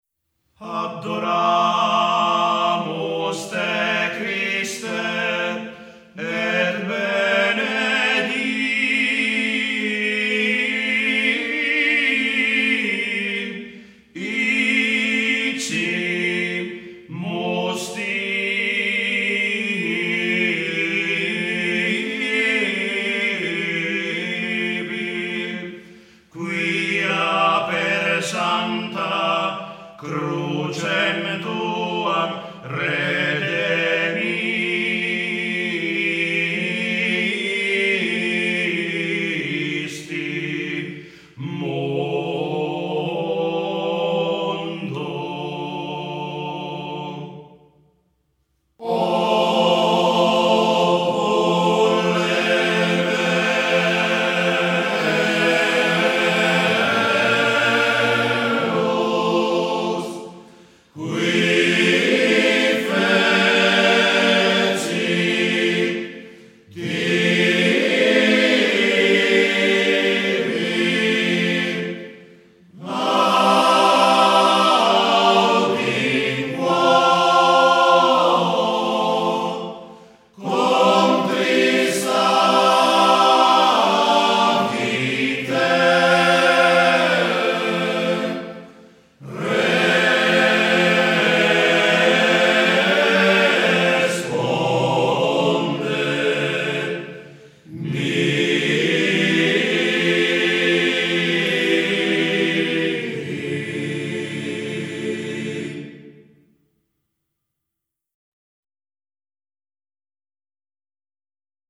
13. Adoramus (canto quaresimale di Salzan di S. Giustina)